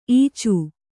♪ īcu